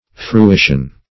Fruition \Fru*i"tion\, n. [OF. fruition, L. fruitio, enjoyment,